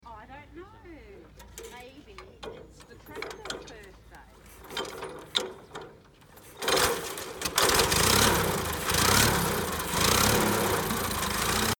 The Cletrac 'Model F' Crawler Tractor starting up at 100 years old
Cletrac 'Model F' Crawler Tractor. 1921.
This short recording was extracted from a video taken on Thursday 7th October 2021 for the tractor's 100th birthday celebration.